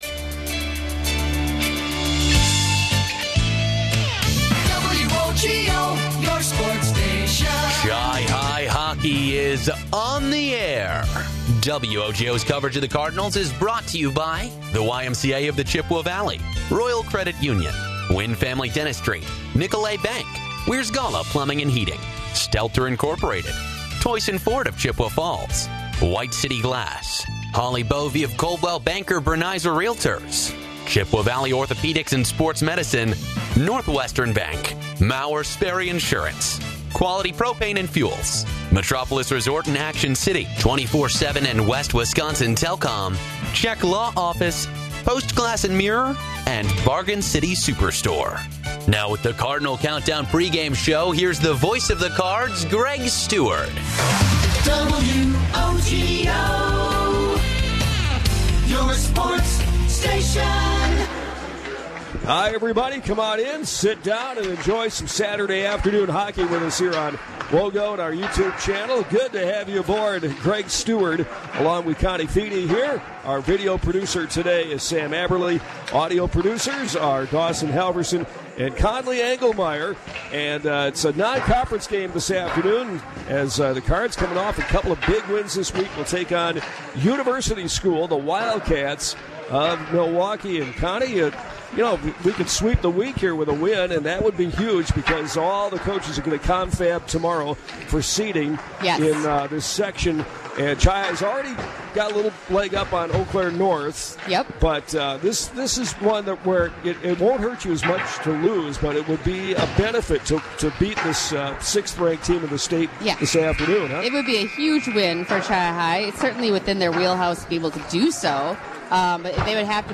with the call from the Chippewa Ice Arena